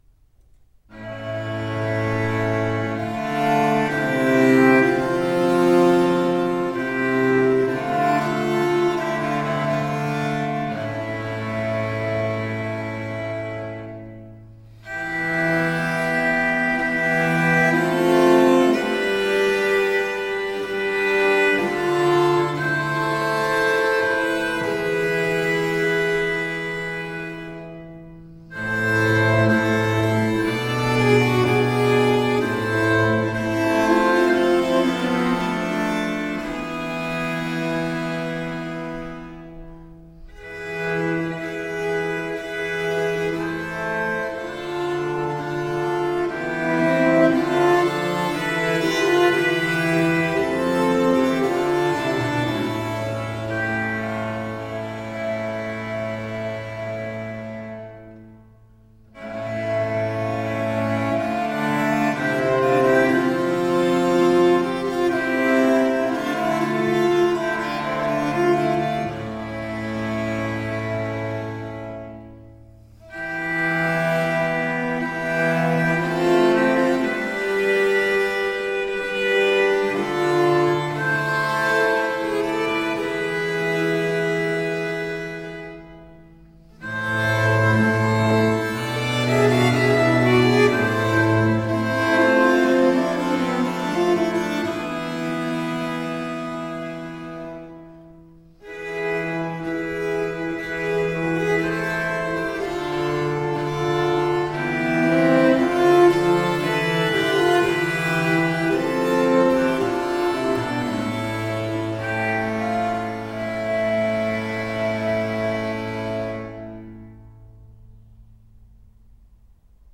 Classical, Renaissance, Classical Singing
Flute, Lute, Viola da Gamba